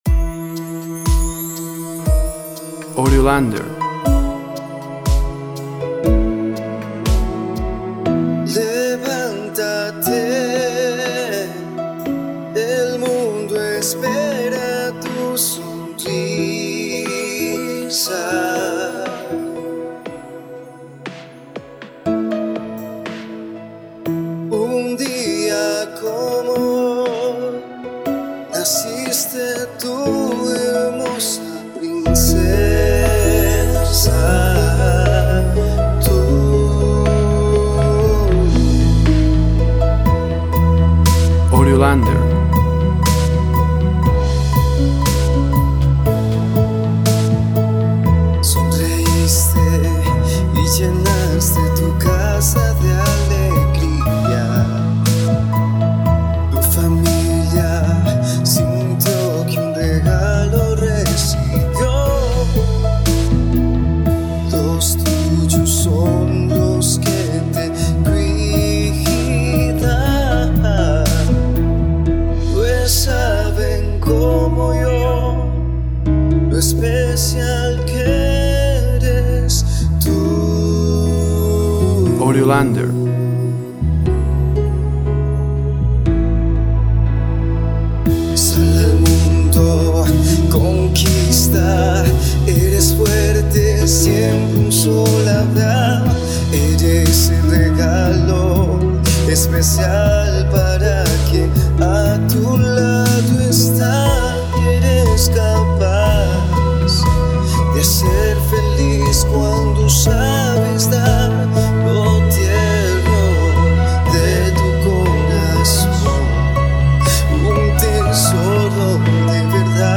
Song for a woman’s birthday, Romantic Love song.
Tempo (BPM) 60